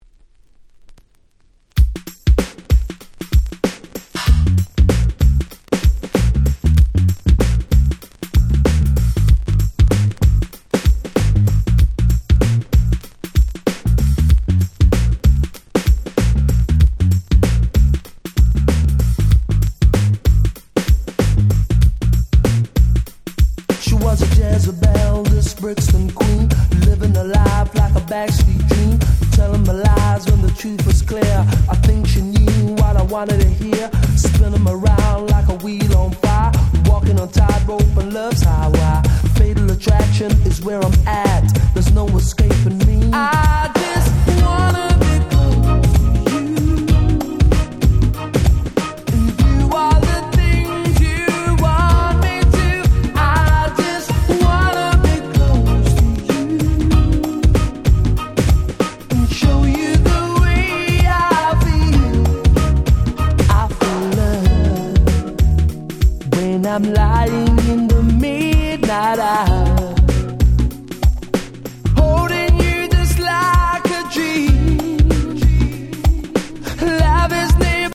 90' Super Hit Reggae / R&B !!